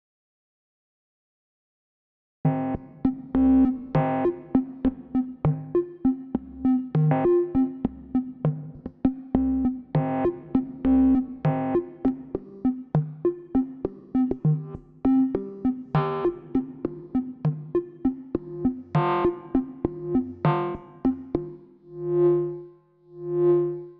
Sequenz aus einem Buchla-System
BuchlaSeq.mp3